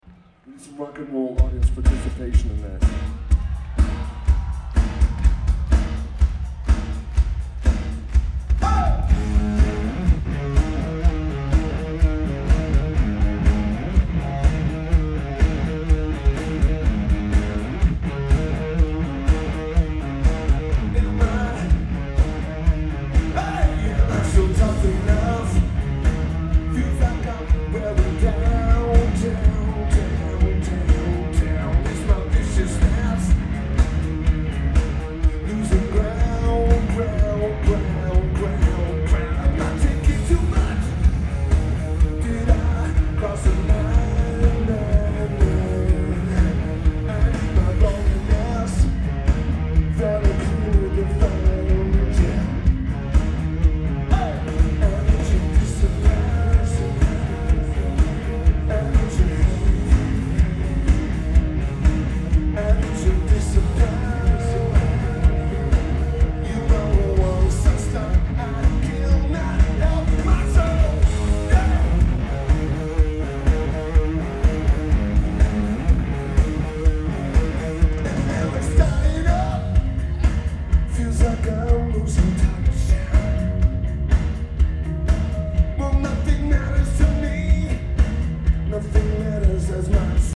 DTE Energy Music Theatre
Drums
Bass
Vocals/Guitar/Keyboards